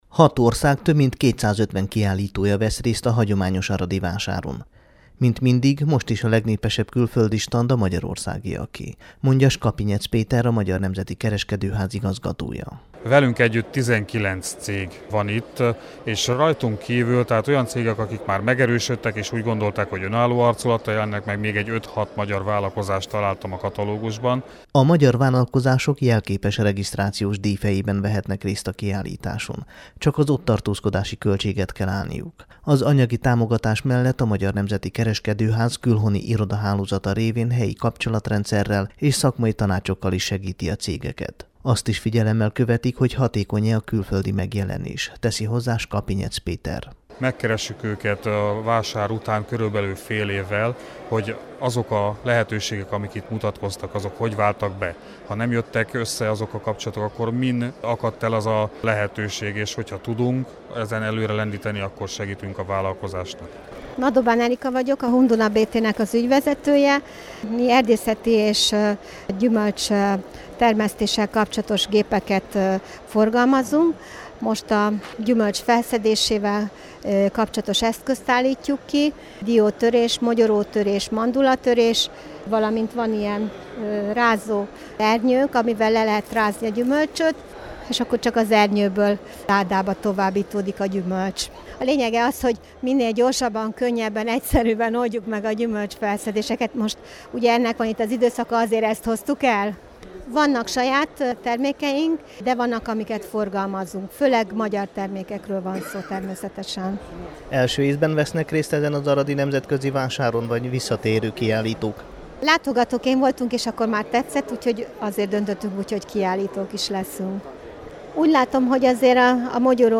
riportot